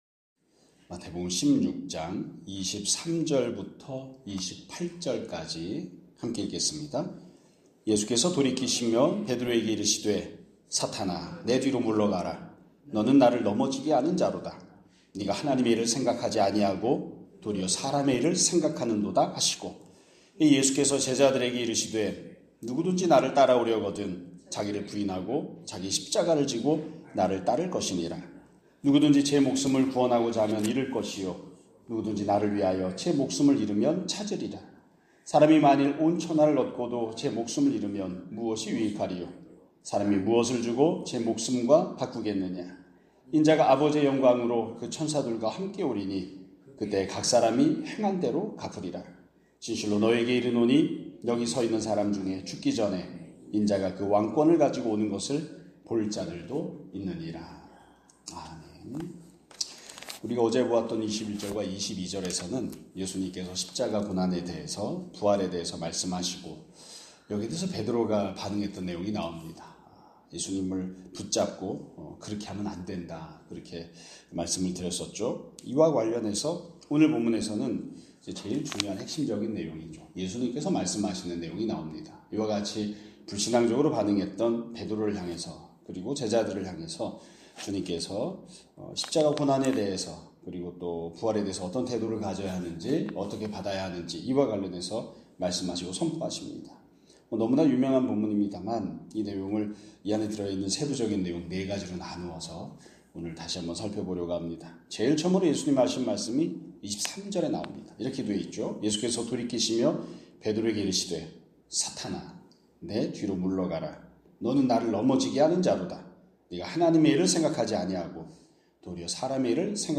2025년 11월 20일 (목요일) <아침예배> 설교입니다.